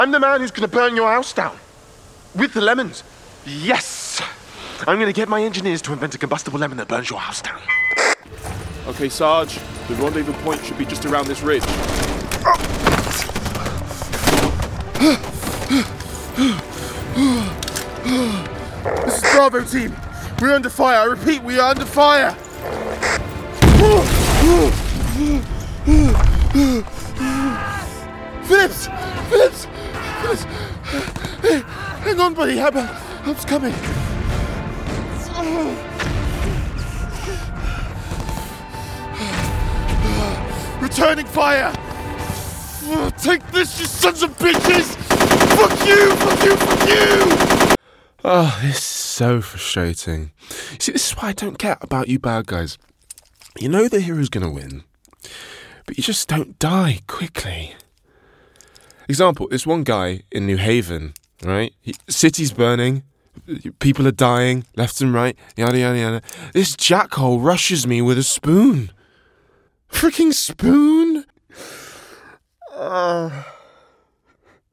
20s-30s. Deep-tone. A collected and silky voice with plenty of humour and warmth. RP.
Computer Games